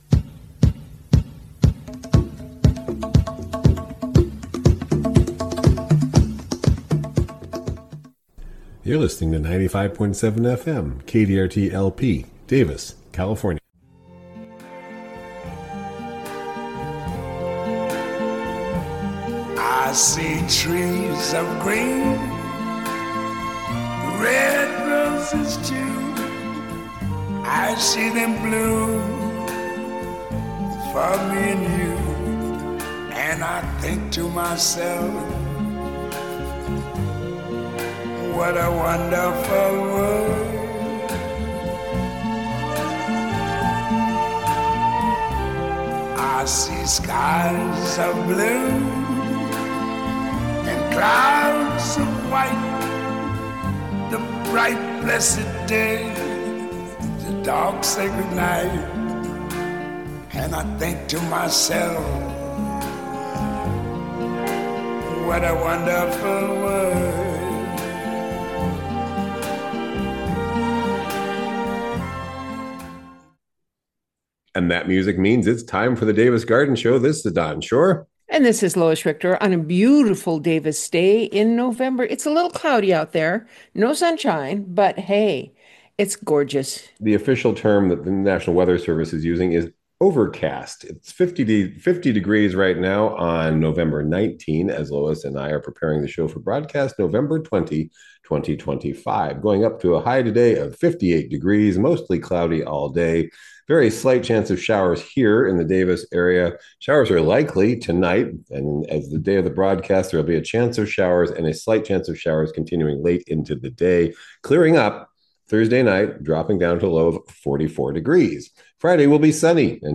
Fall gardening conversations